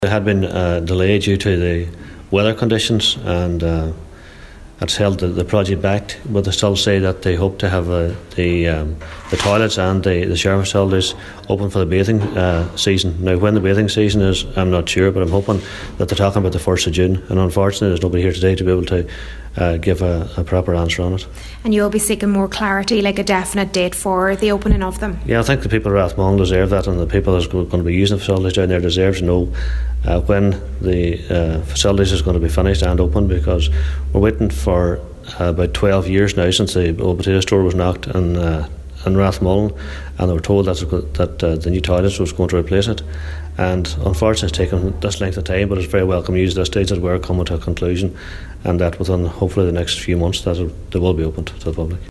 Cllr. Liam Blaney says while it’s positive that a conclusion is in sight, the community deserve a more definite date as to when the facilities will open: